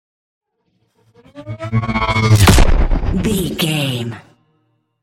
Pass by fast vehicle engine explosion sci fi
Sound Effects
Fast
futuristic
intense